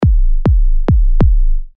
忙碌的放克踢
标签： 140 bpm Funk Loops Drum Loops 297.79 KB wav Key : Unknown
声道立体声